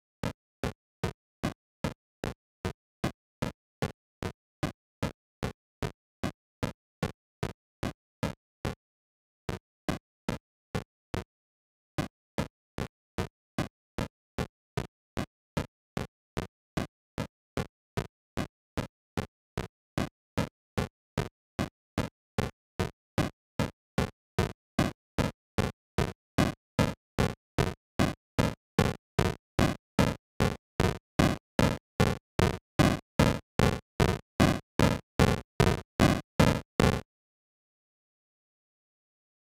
It’s quite difficult to tell from the wave form where the significant increases are, but I think I’ve found a few.
I would imagine, as an old 8-bit game, there is probably another “chunk” in there somewhere, as 7 levels would seem odd, I’d expect 8 levels of volume, this is probably me just missing one of the increases.
Looking at the playback levels indicator at the top of Audacity, the first marker is placed around -10, after some time (about 20 seconds in on the attached .ogg file - the .wav was too large to attach (6.6mb)) it starts to increase (or perhaps that should say decrease) from -10 to about -8, then -6 then -4 - it doesn’t ever reach 0 so guessing its about a -1 at the end.